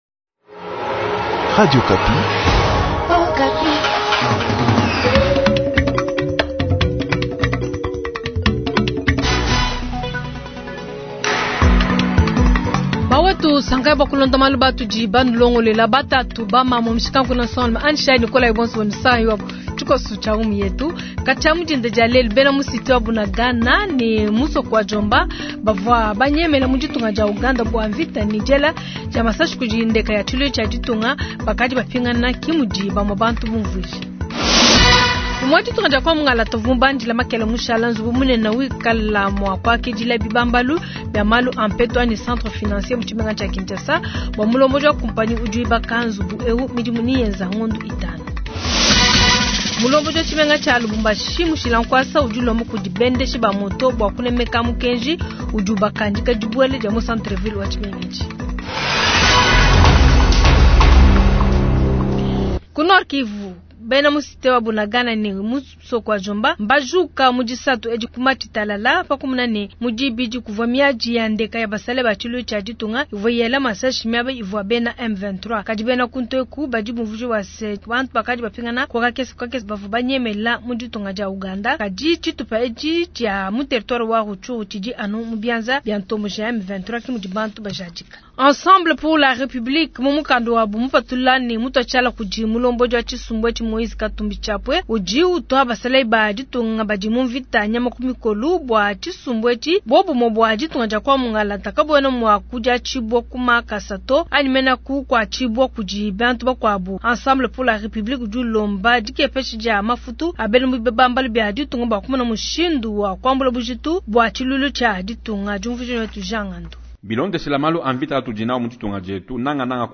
Journal soir